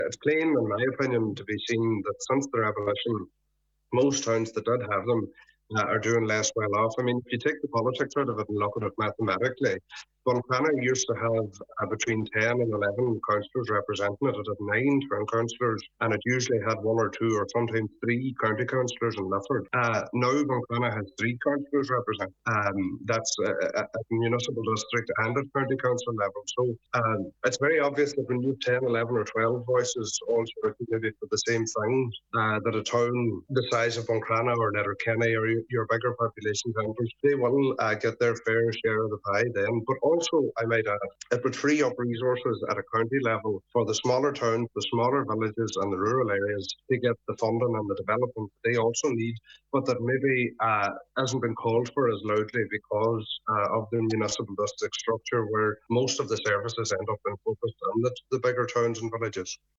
on today’s Nine ’til Noon show about how significant a reintroduction could be to Donegal: